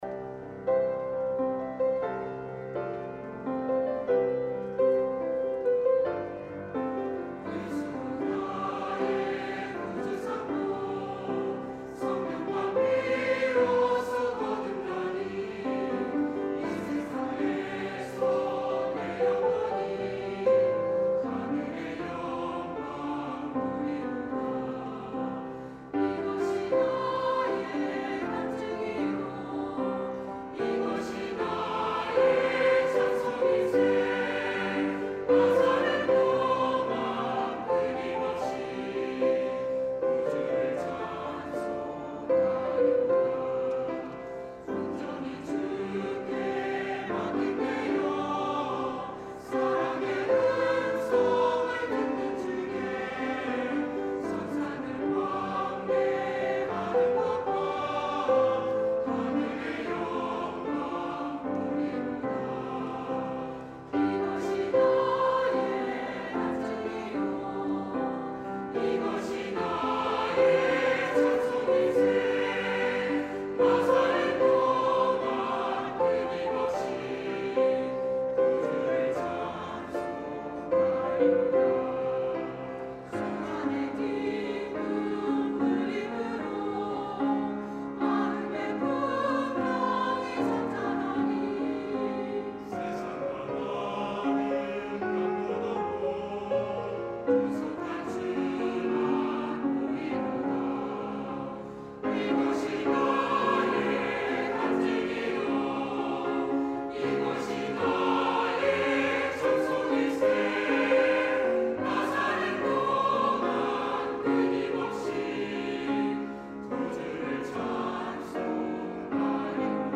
찬양 :: 140727 예수로 나의 구주삼고